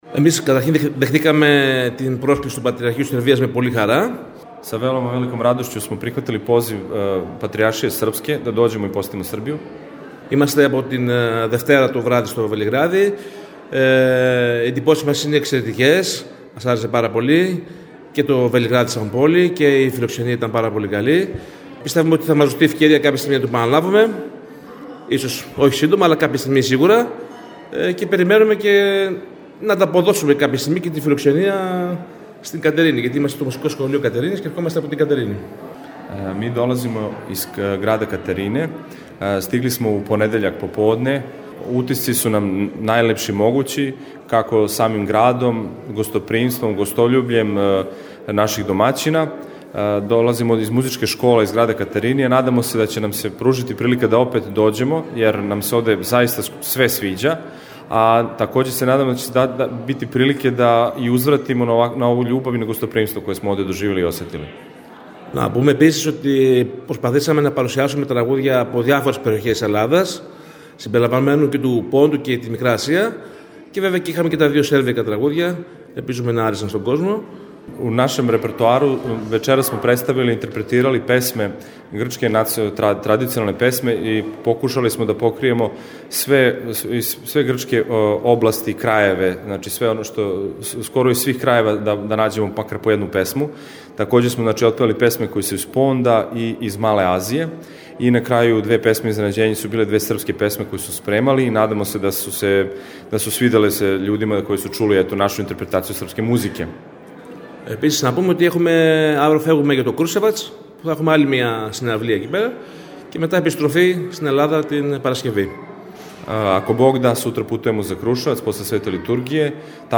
Изјаве за Радио Слово љубве